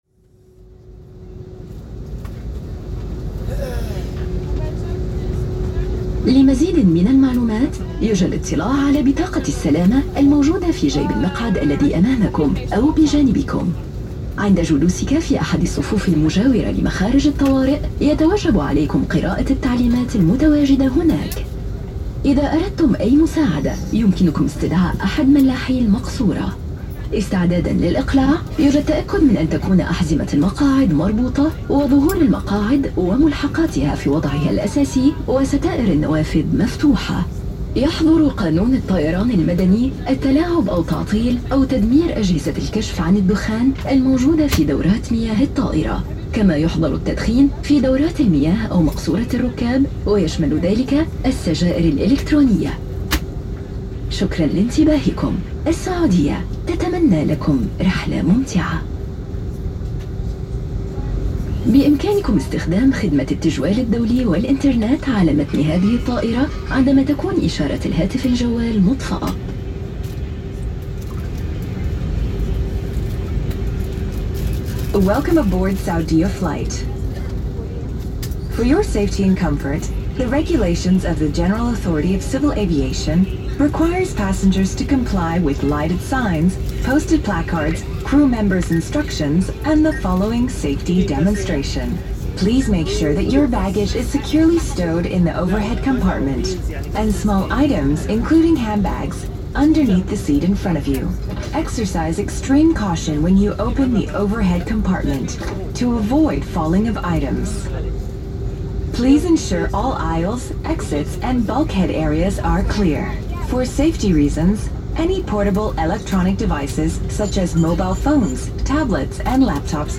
Pre-flight supplication to Allah, Saudi Arabia
Before an internal flight from Jeddah to Riyadh, a supplication to Allah for safety in travel is broadcast after the regular safety announcements you'd expect to hear on a flight - a sonic feature unique to the region, this one is from a Saudia flight.